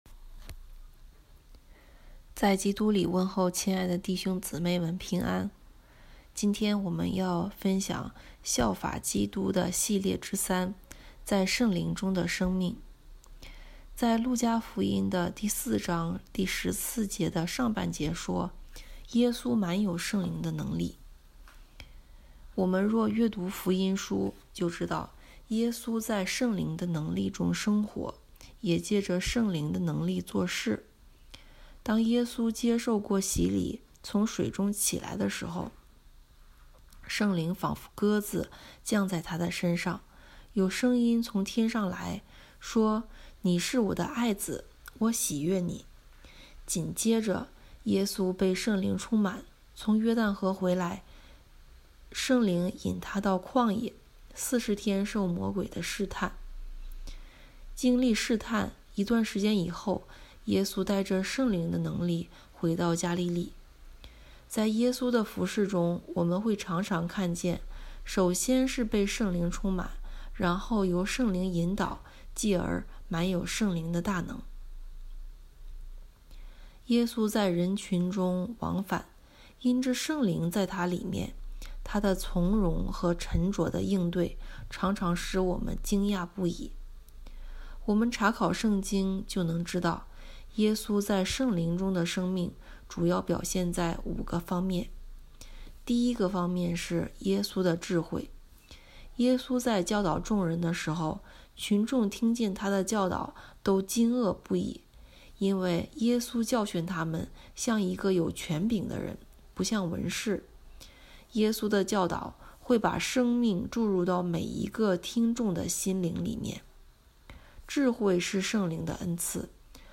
效法基督之三：在圣灵中的生命 | 北京基督教会海淀堂
证道